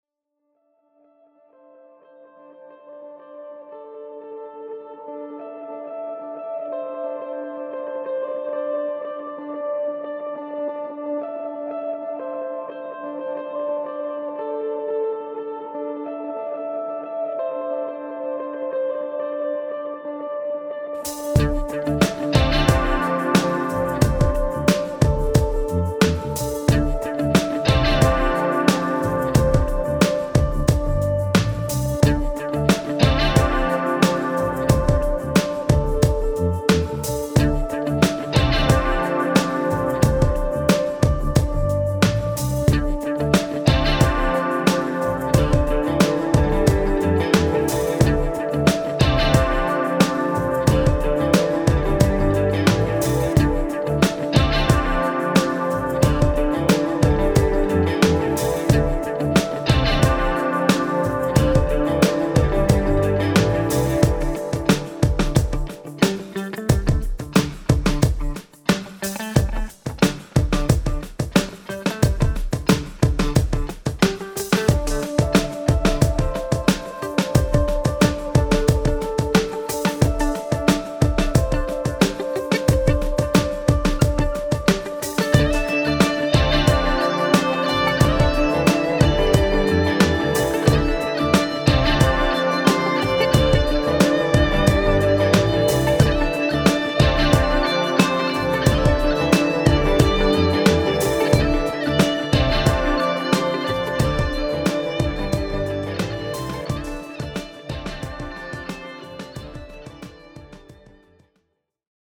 Ein kurzer Track a la The Police!